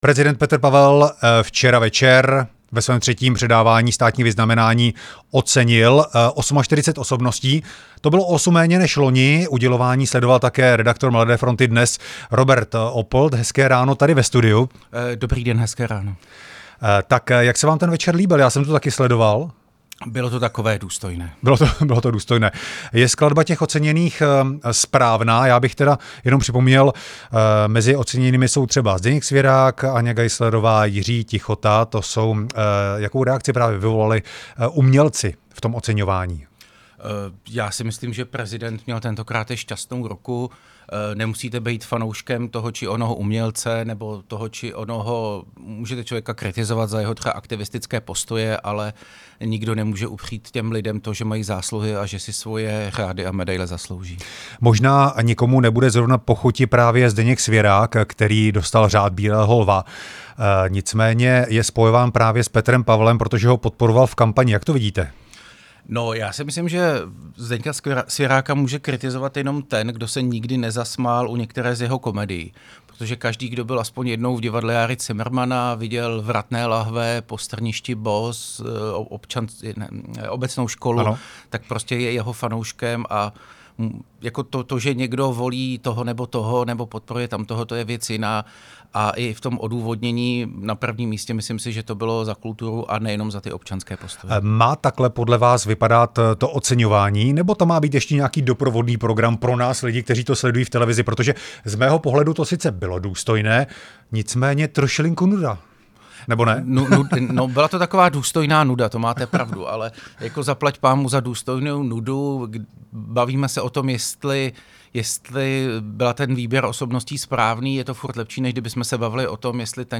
host rádia prostor
Rozhovor